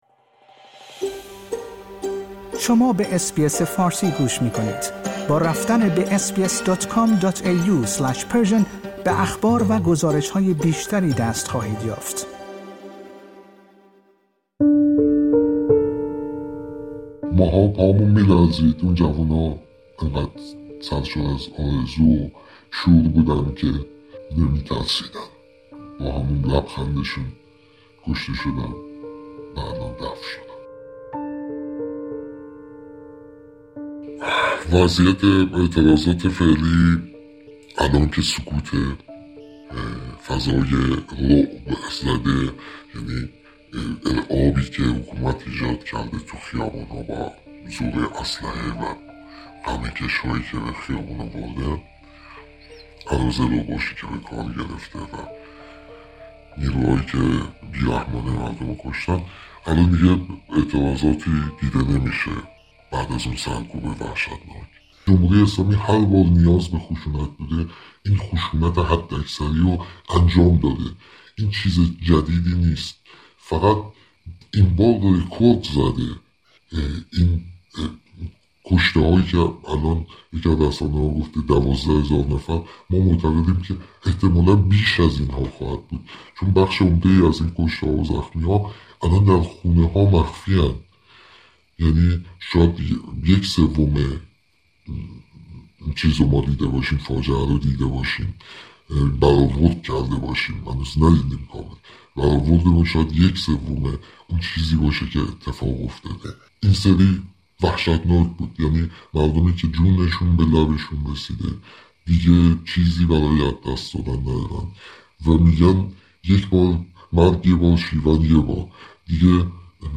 یک فعال حقوق بشر در ایران در گفت‌وگویی اختصاصی با اس‌بی‌اس فارسی از خیابان‌ها و اعتراضات سراسری روزهای گذشته می‌گوید.